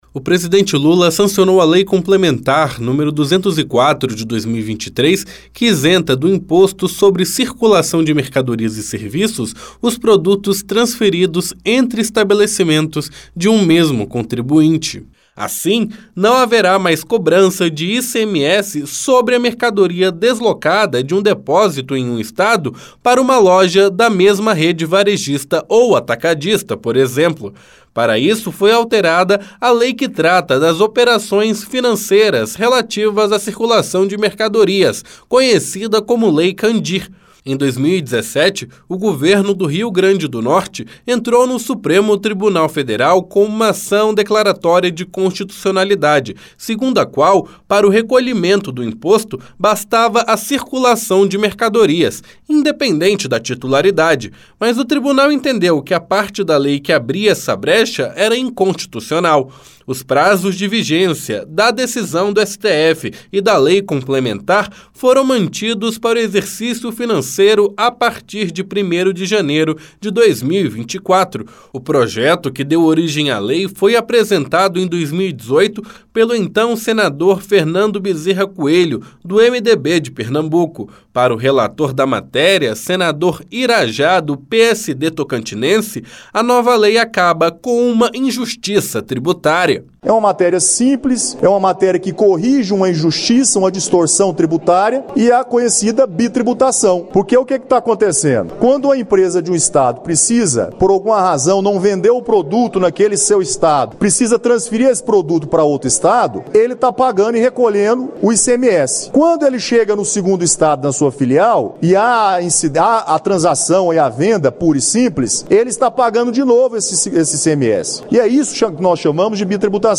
2. Notícias
Para o relator, senador Irajá (PSD/TO), a mudança é justa, pois acaba com a bitributação que afetava setores atacadistas e varejistas.